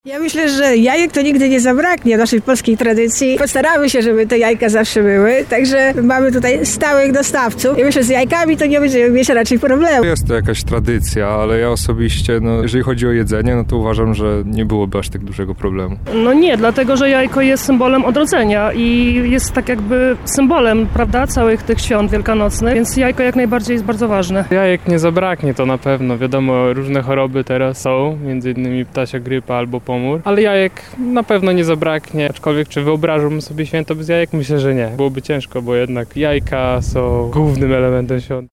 Zapytaliśmy Lublinian czy wyobrażają sobie święta bez tego produktu spożywczego: